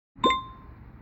吃金币音效.mp3